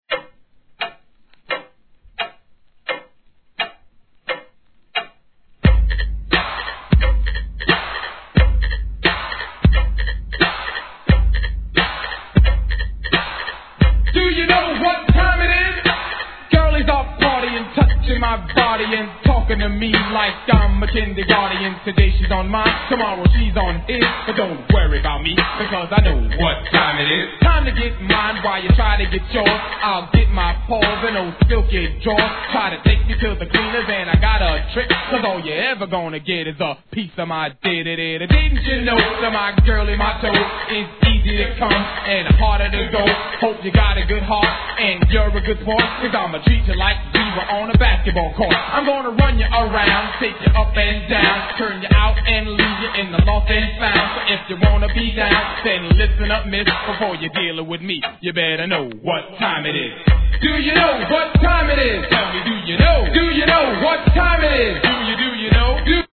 HIP HOP/R&B
時計の針の刻む音にいかにも'80sなドラムパターンが憎めない1987年作品!